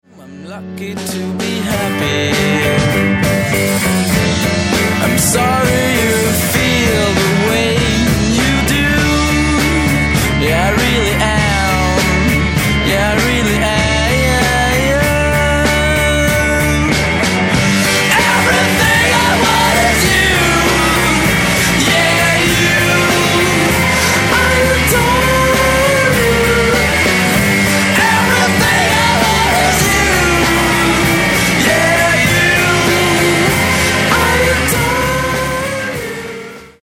Guitar Pop/Swedish